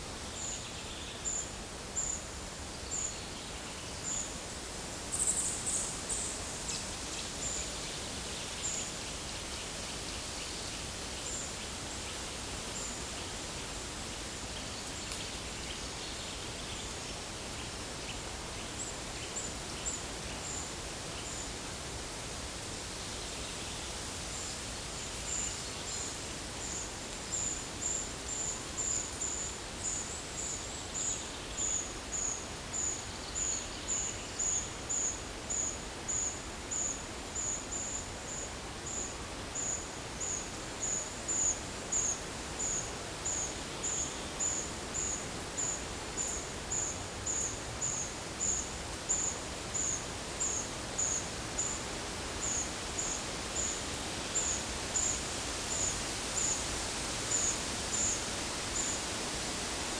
Monitor PAM
Certhia familiaris
Certhia brachydactyla
Sitta europaea